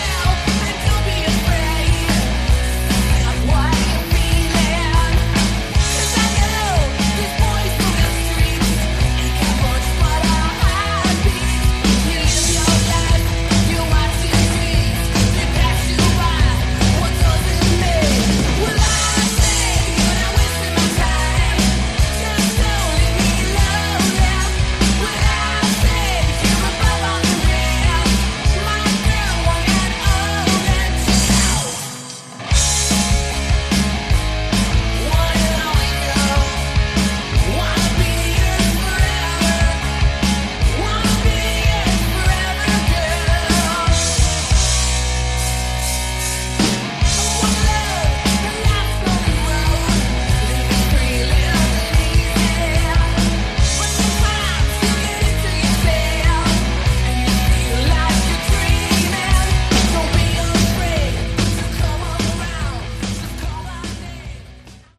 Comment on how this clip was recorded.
Good songs, shame about the production.